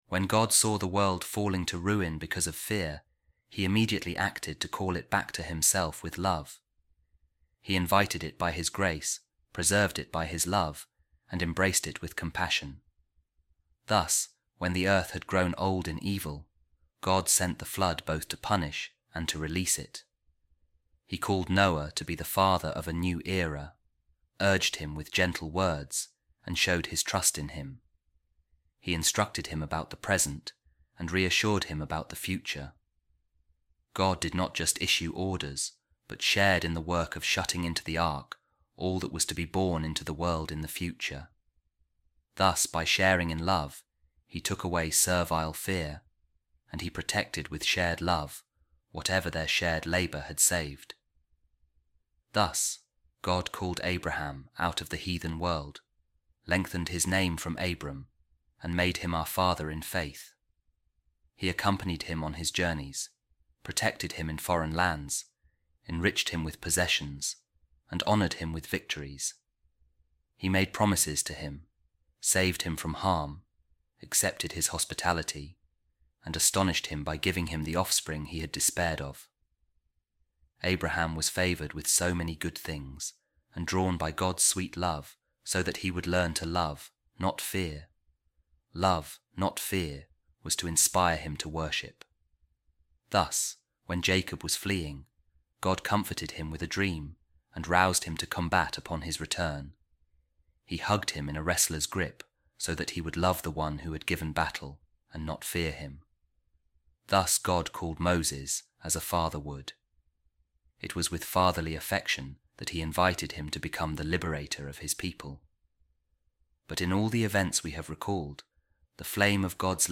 A Reading From A Sermon By Saint Peter Chrysologus | Love Desires To See God